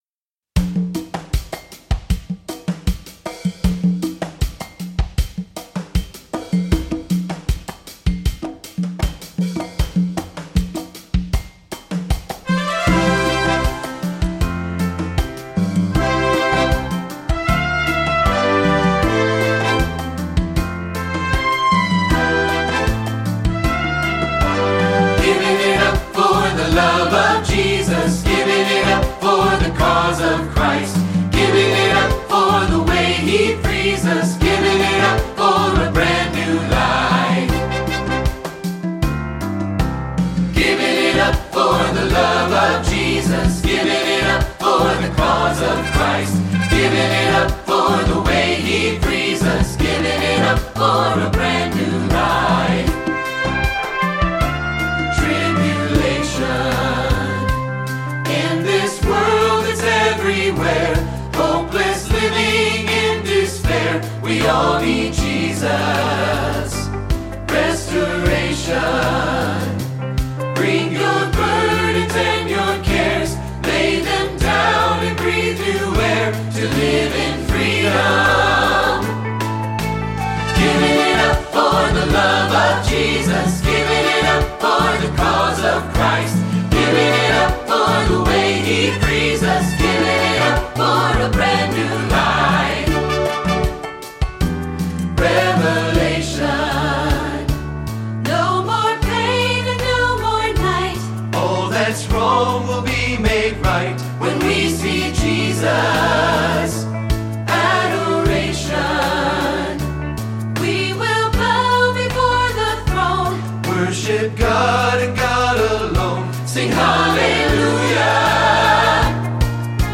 An easy and fun to sing anthem with a Caribbean/Latin American sound.